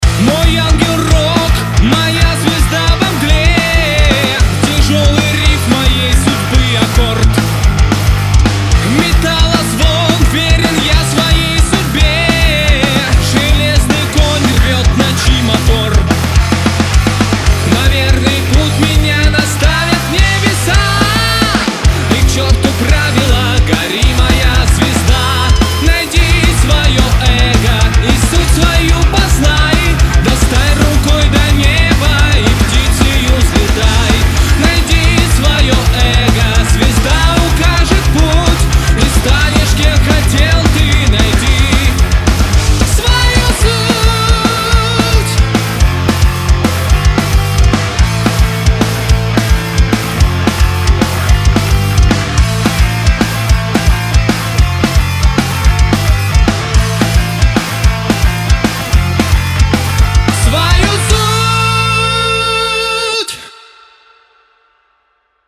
Heavy/Power metal на оценку
Уровень музыкантов не самый высокий, но хочется сделать максимально хорошо.